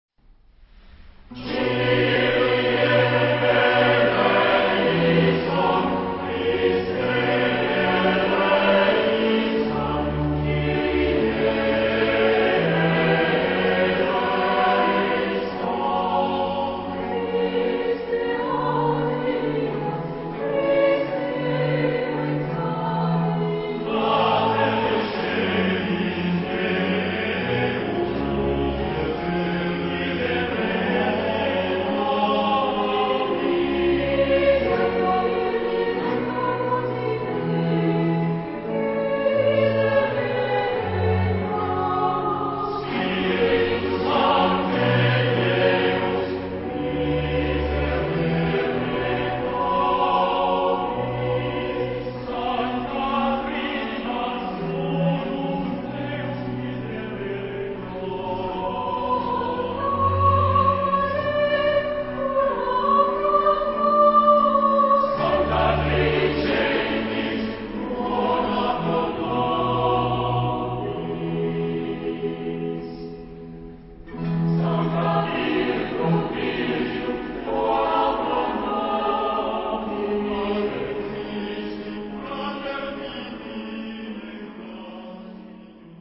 ... Alternance de TUTTI et de SOLI (1-2 ou 3 Soli)....ad libitum ...
Genre-Style-Forme : Litanie ; Motet ; Baroque ; Sacré
Type de choeur : SATB  (4 voix mixtes )
Instrumentation : Basse continue  (2 partie(s) instrumentale(s))
Instruments : Basson (1)/Violoncelle (1) ; Clavecin (1) ou Orgue (1)
Tonalité : do mode de ré
Réf. discographique : Aria voce en concert - 5ème anniversaire - MRO11